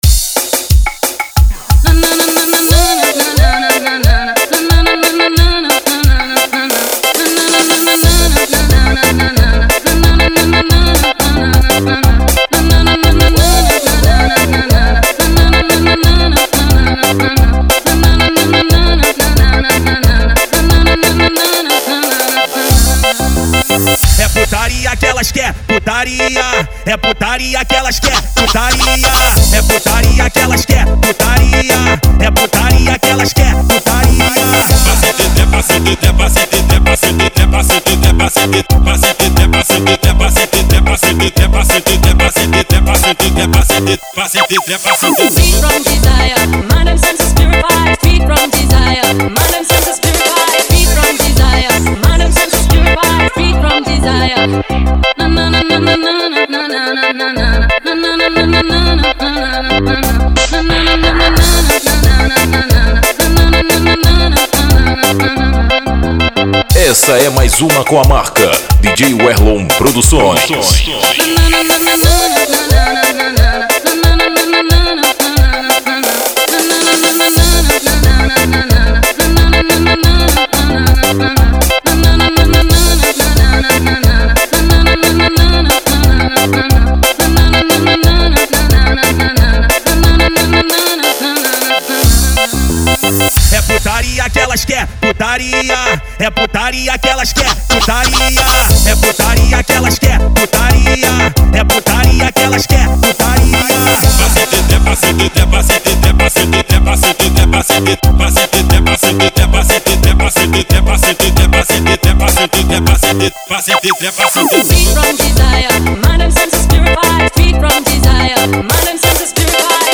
OUÇA NO YOUTUBE Labels: Tecnofunk Facebook Twitter